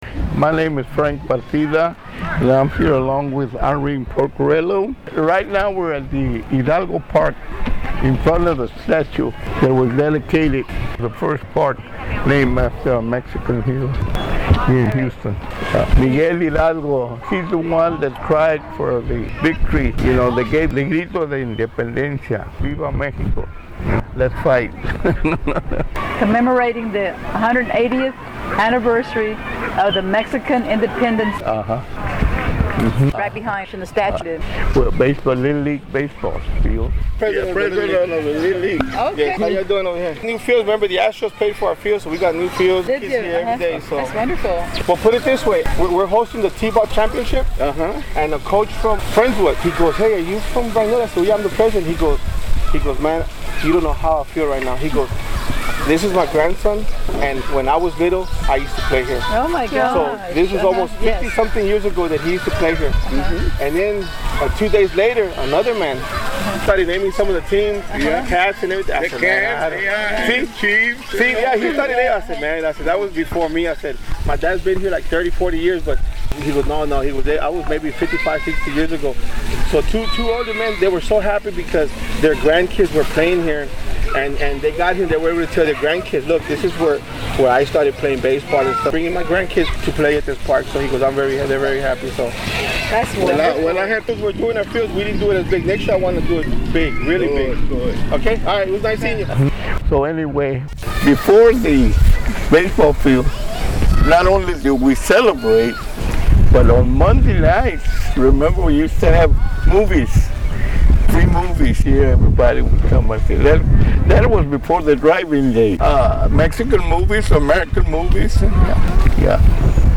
40 minutes 6 miles, walking and driving